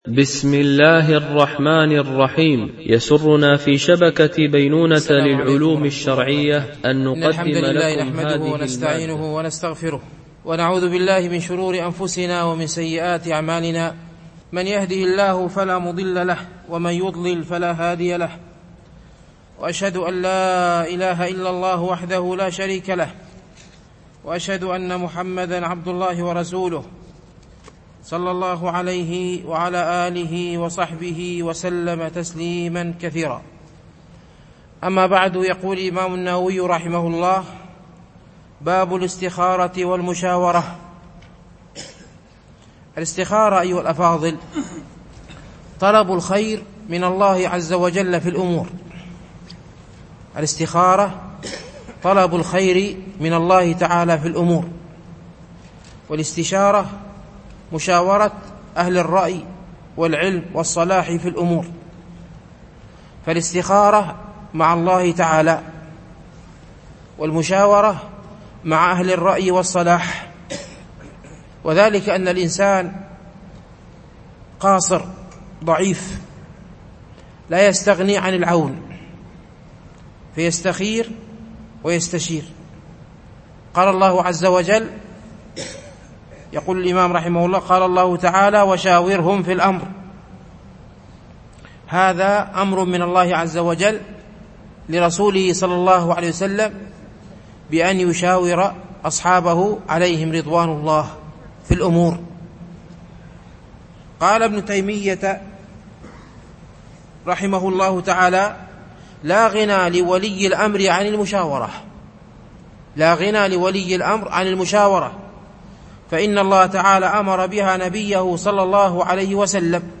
شرح رياض الصالحين - الدرس 199 (الحديث 718)